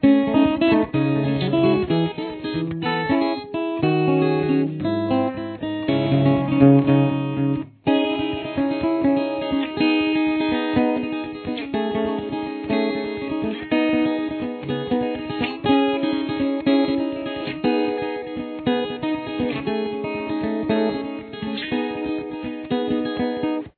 CAPO – Fourth Fret
Guitar Solo
Interlude